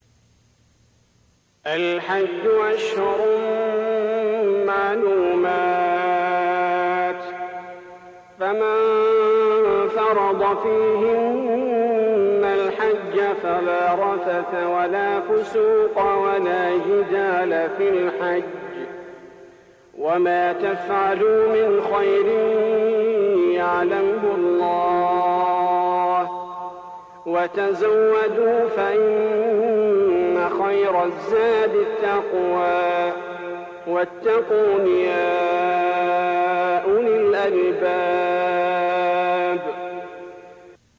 Recitation Holy Qur'an